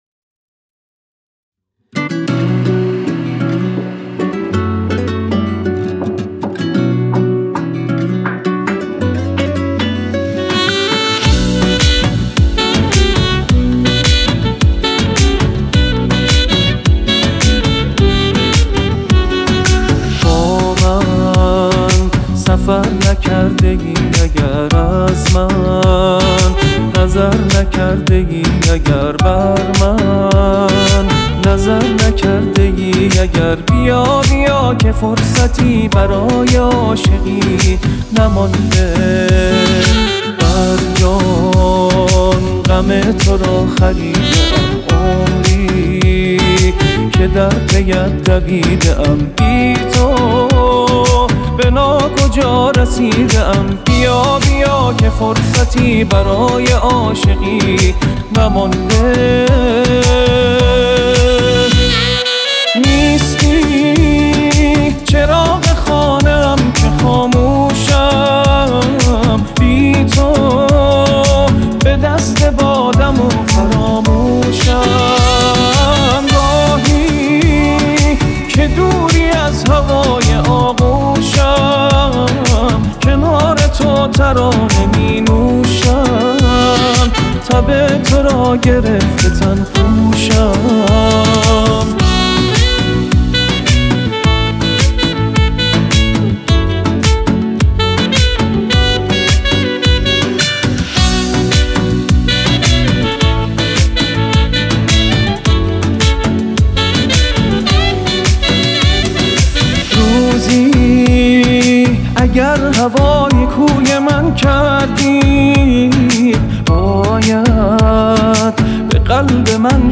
منتظر یه آهنگ پر از حس خوب و انرژی مثبت بودید؟